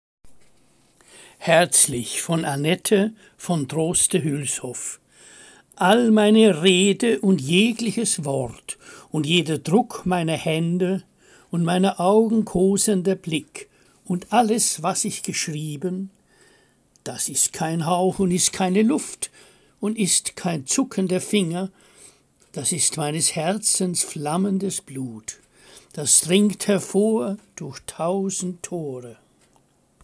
Lesung Gedichte aus der Romantik